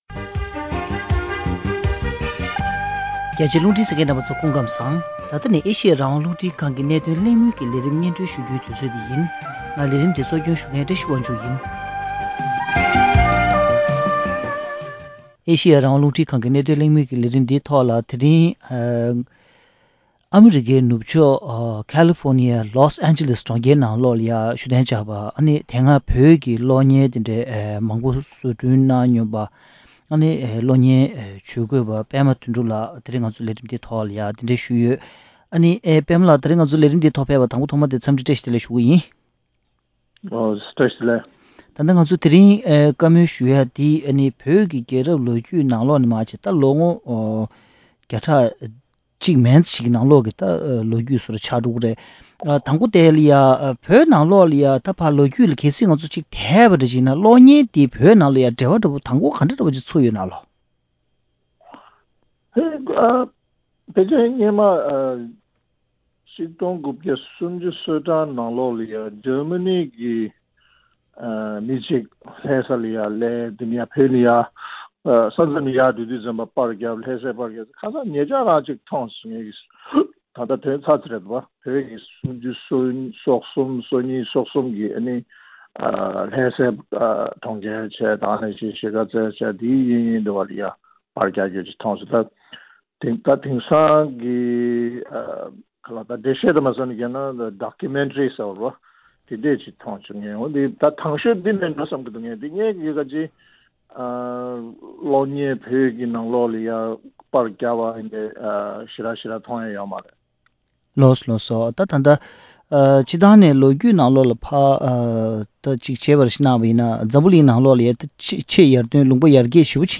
གློག་བརྙན་སྒྱུ་རྩལ་དང་བོད་ཀྱི་ལོ་རྒྱུས་དབར་གྱི་འབྲེལ་བ་དང་། སྒྱུ་རྩལ་སྤྱི་ཚོགས་ནང་རྒྱ་ནག་གིས་ཐེ་བྱུས་བཅས་ཀྱི་སྐོར་གླེང་མོལ་ཞུས་པ།